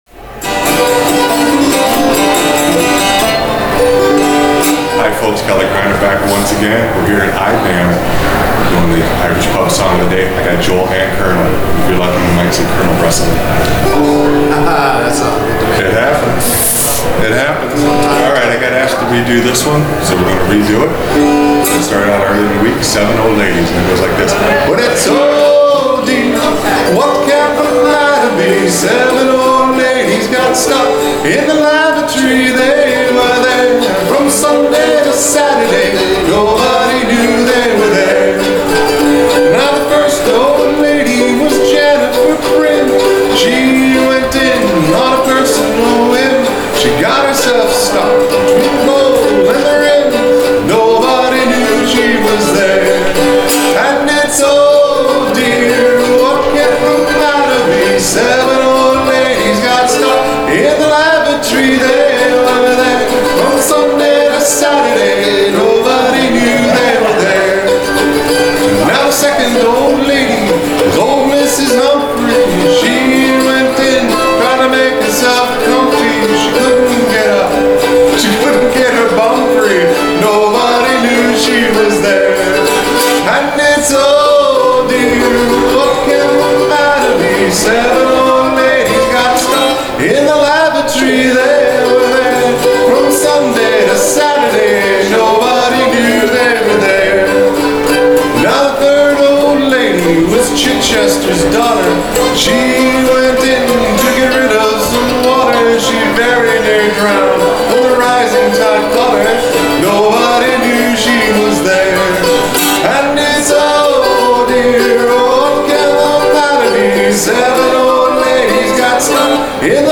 Clawhammer BanjoFrailing BanjoInstructionIrish Pub Song Of The Day
Live from iBAM! for the Irish Pub Song Of The Day.